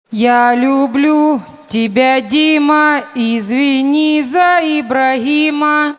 • Качество: 128, Stereo
веселые
Sms сообщение
с юмором